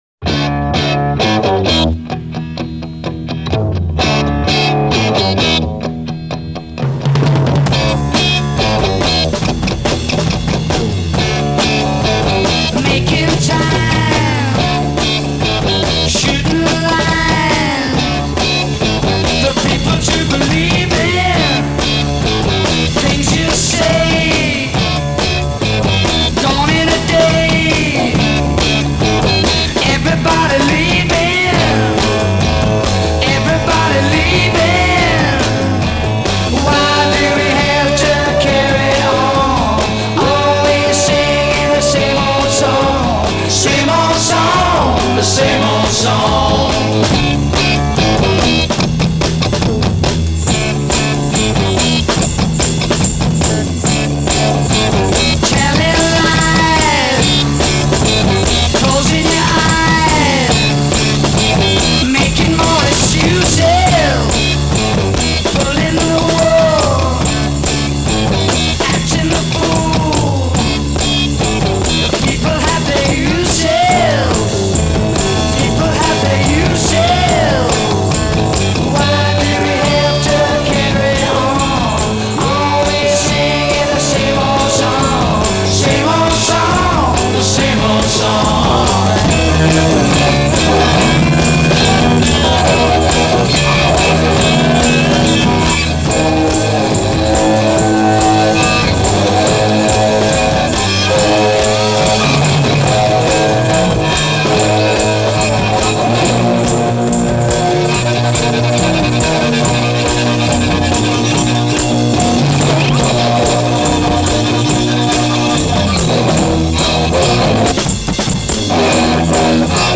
the riff is timeless, the production garage-esque and grungy